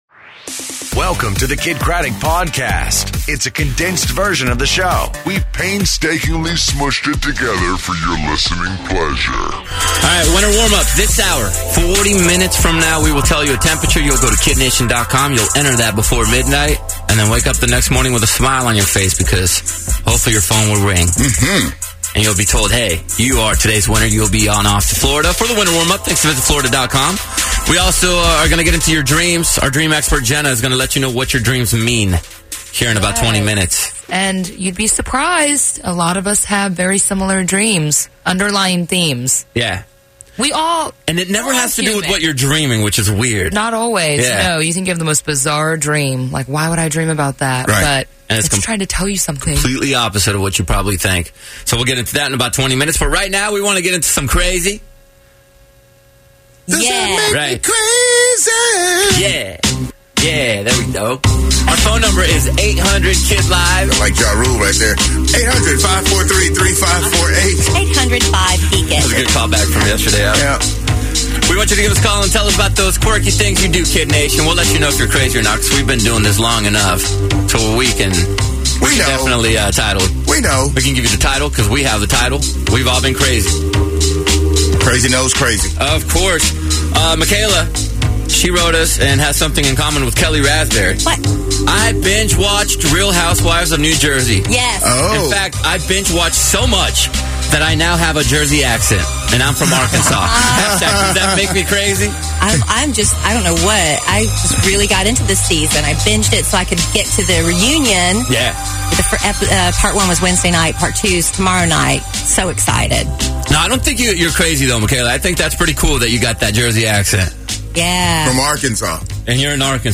Does That Make Me Crazy!? Gwen Stefani Calls The Show, And Dream Analysys.